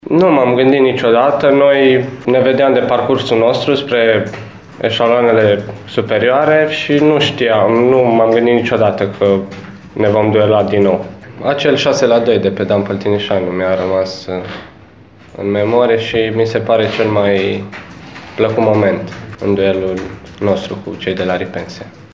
La conferința de presă de astăzi, alb-violeții au declarat că și-ar fi dorit ca meciul să se desfășoare pe stadionul „Dan Păltinișanu”, acolo unde numărul spectatorilor ar fi fost cu siguranță mai mare.